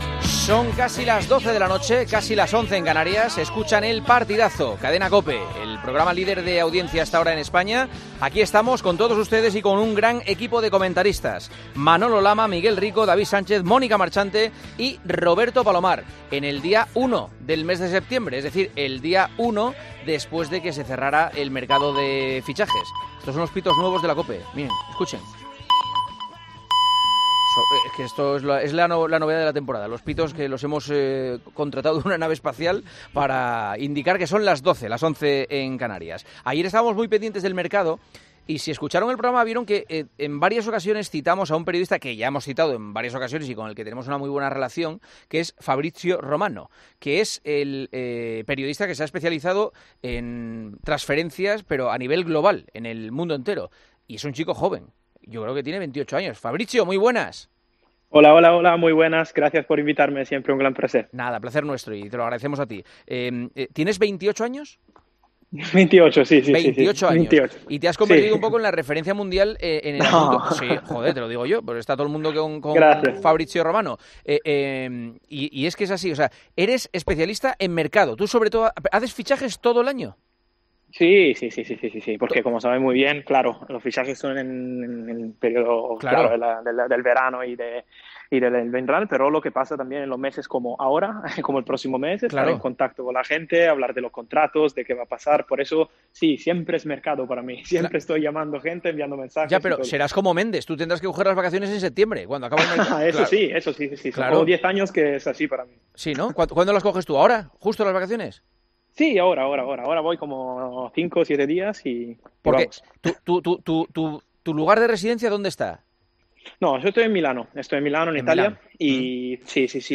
AUDIO: Hablamos con el periodista de SkySports, especializado en el mercado de futbolistas, sobre su trabajo.